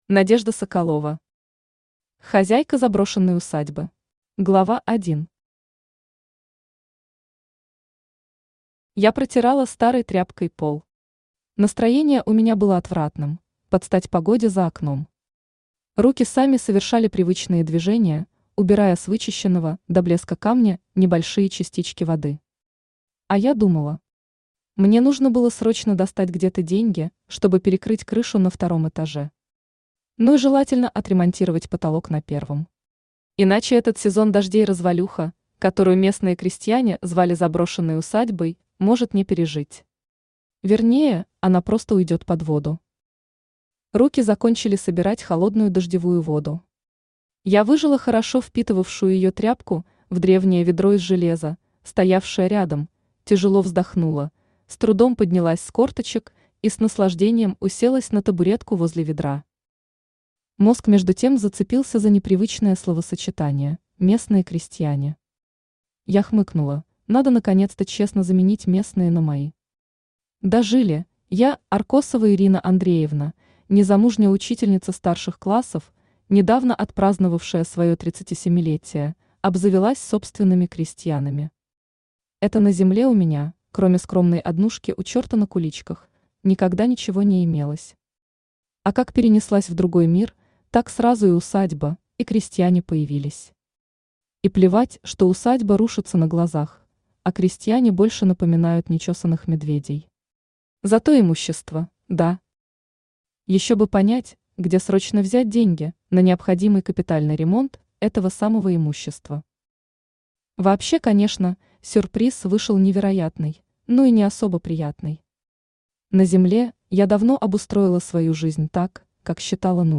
Аудиокнига Хозяйка заброшенной усадьбы | Библиотека аудиокниг
Aудиокнига Хозяйка заброшенной усадьбы Автор Надежда Игоревна Соколова Читает аудиокнигу Авточтец ЛитРес.